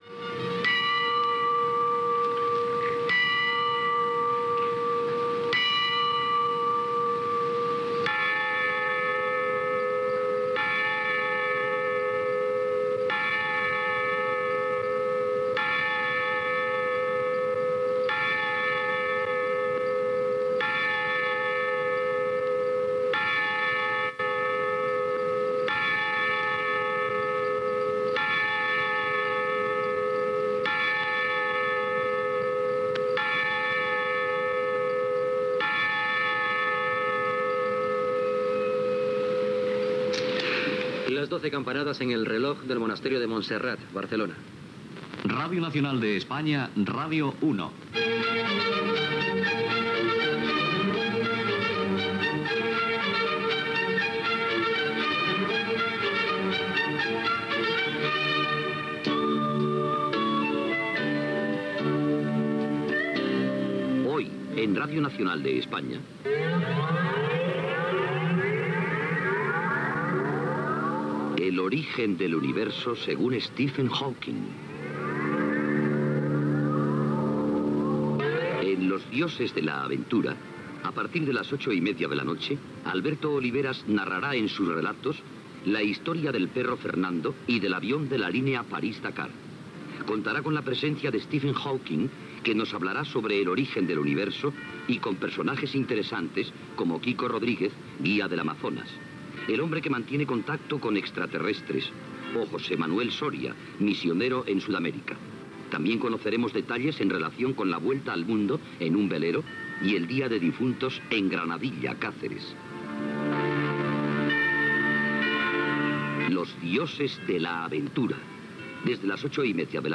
Campanades de les 12 del migida des del Monestir de Montserrat, indicatiu de l'emissora ( com Radio 1), promoció del programa "Los dioses de la aventura"